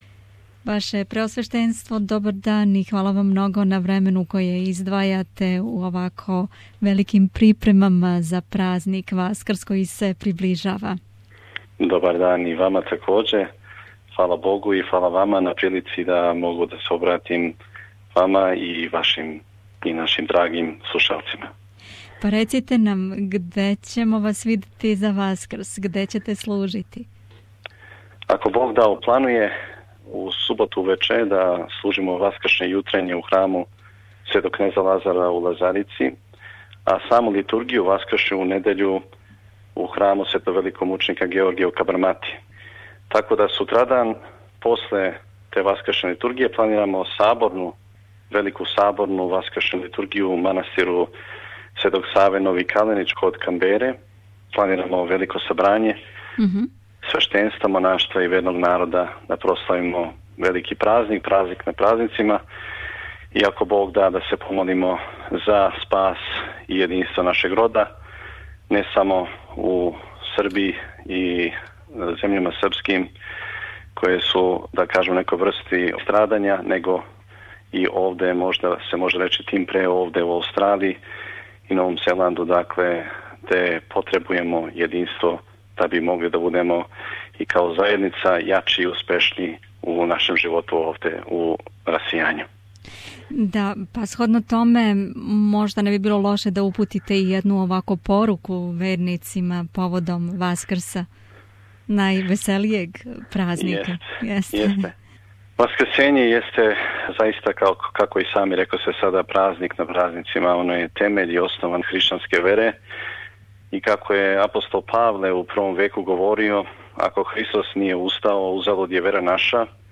Негово преосвештенство Епископ Митрополије аустралијско-новозеландске, Господин Силуан (Мракић) разговарао је са нама пред Васкрс.